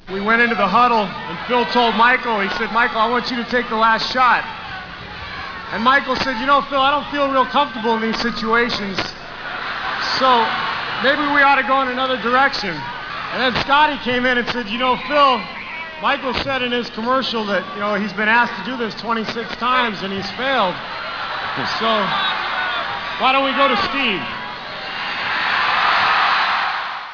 Steve talks about "The Shot" (wav)
rally.wav